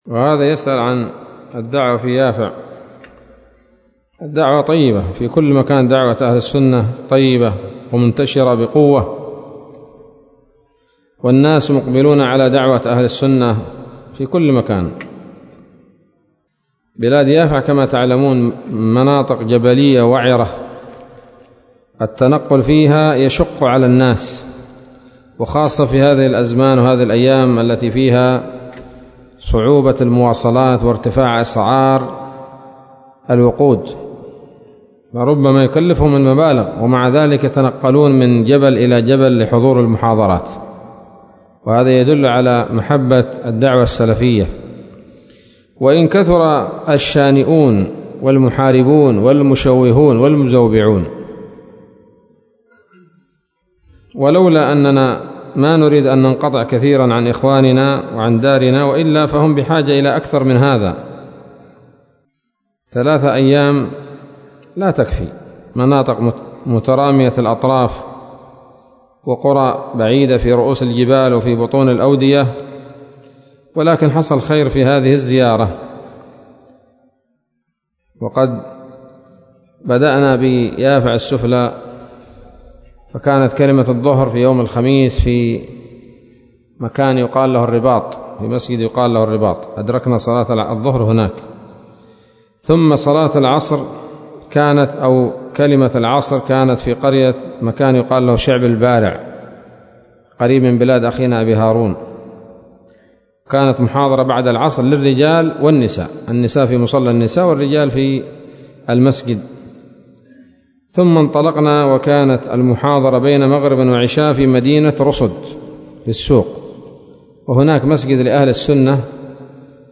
كلمة قيمة بعنوان: (( ‌شرح الرحلة إلى بلاد يافع )) ليلة الثلاثاء 12 شعبان 1443هـ، بدار الحديث السلفية بصلاح الدين